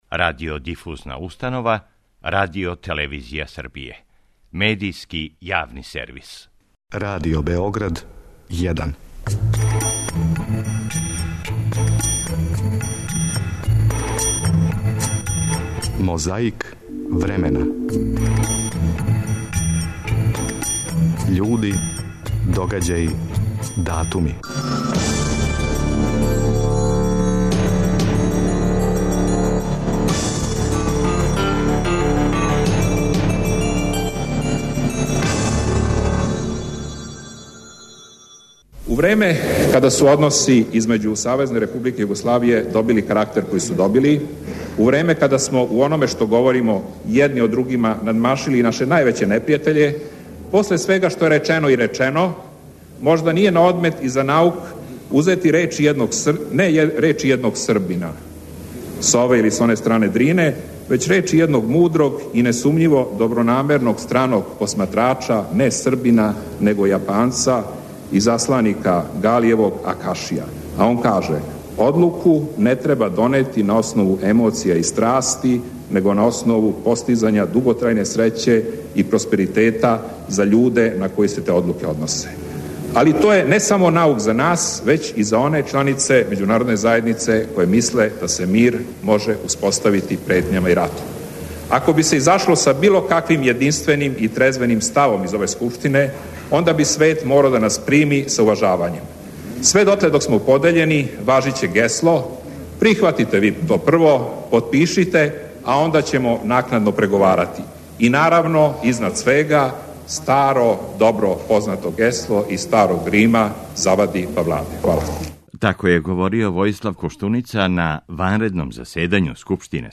Чућете шта је говорио на ванредном заседању Скупштине Србије, 25. августа 1994. Вођена је расправа о предлогу мировног плана контакт групе за окончање рата у Босни.